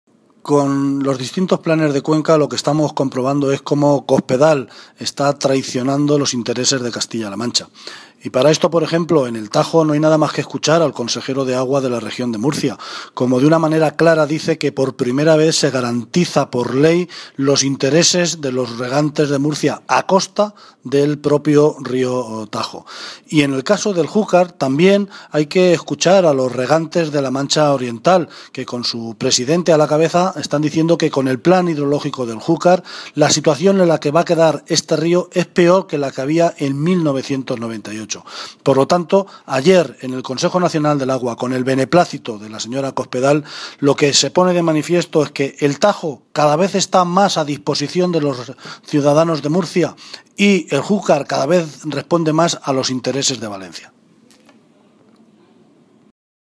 Cortes de audio de la rueda de prensa
Audio Martínez Guijarro